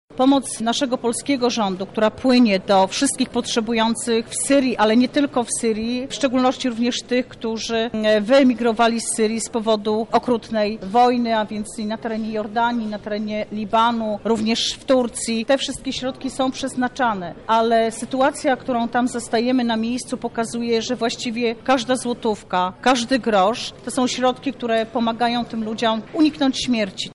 Wkład województwa lubelskiego w pomoc Syryjczykom doceniła Minister ds. pomocy humanitarnej i członek Rady Ministrów Beata Kempa,